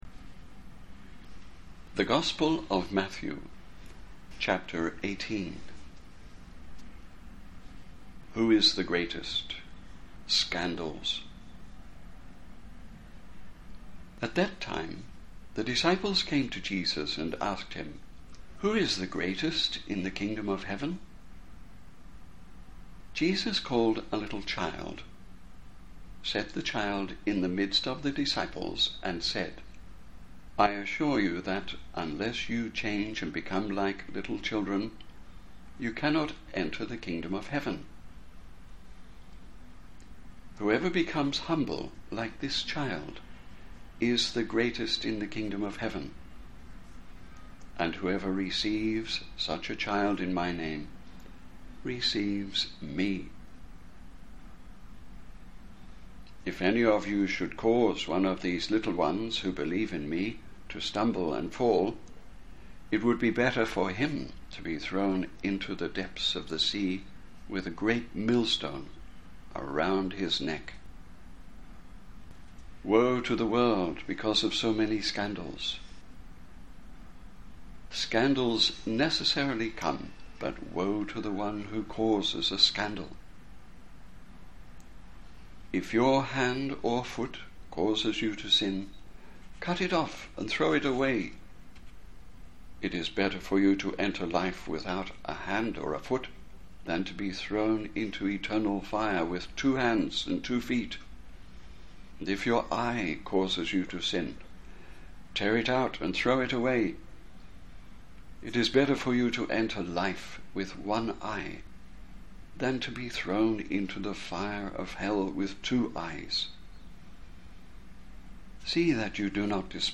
A meditate reading
slow enough to meditate and pray with the text